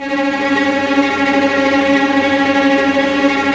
Index of /90_sSampleCDs/Roland - String Master Series/STR_Vcs Tremolo/STR_Vcs Trem f